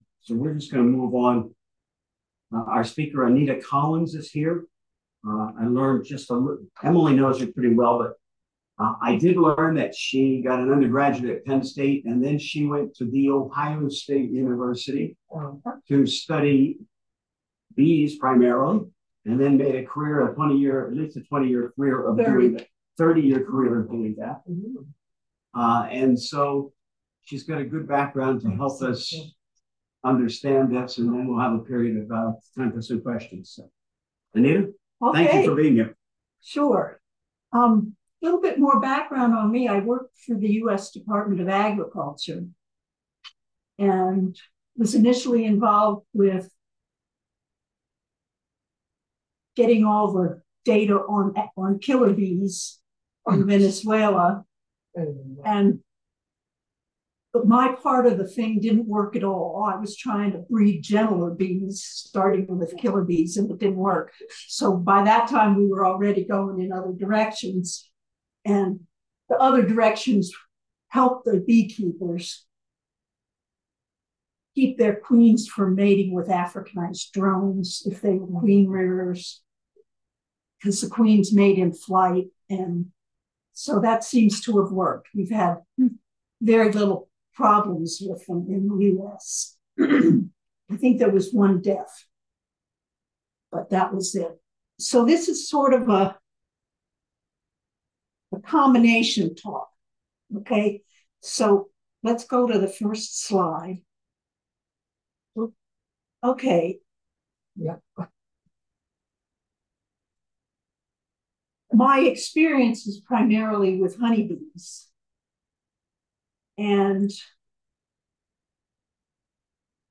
2024 Environmental Management Council Meetings